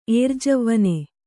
♪ ērjavvane